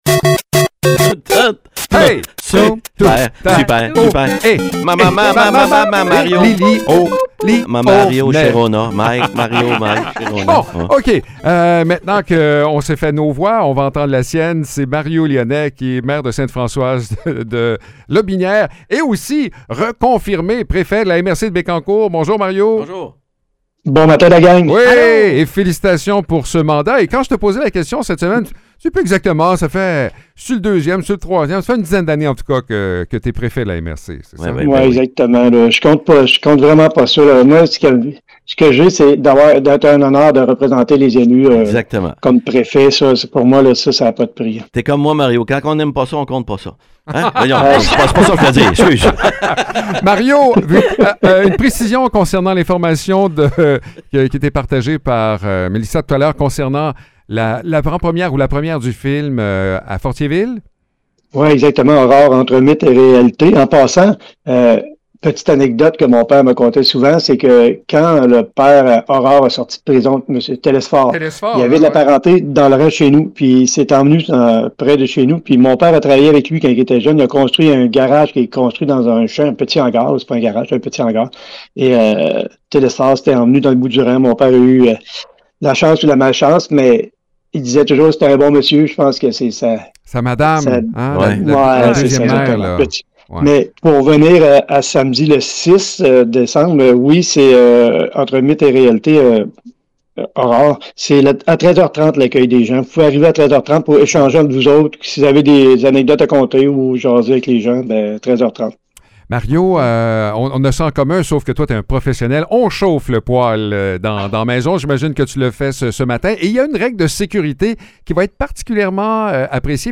Mario Lyonnais, maire de Sainte-Françoise et préfet de la MRC de Bécancour, partage une anecdote marquante liée à Aurore, une histoire qui nourrit l’imaginaire depuis plus de 100 ans. Avec l’arrivée du froid, il en profite aussi pour rappeler l’importance des bonnes pratiques concernant la disposition sécuritaire des cendres.